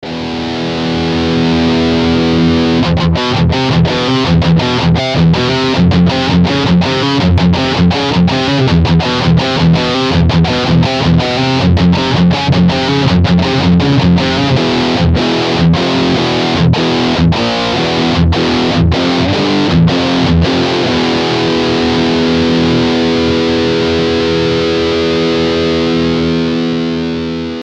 Ето и един кратък пример с не особено харесваният босс метал зоне 2:
Със спийкър симулация:
Boss MT2 + Speaker Simulation
Kитара (Yamaha RGX420, включена на бридж адаптер - Seymour Duncan JB)
Дисторшън (Boss Metal Zone MT2)
mt2-speaker-sim.mp3